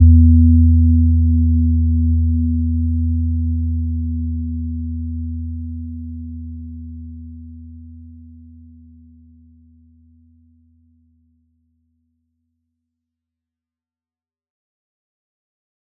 Gentle-Metallic-1-E2-p.wav